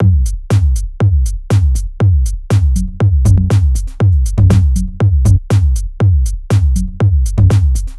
NRG 4 On The Floor 028.wav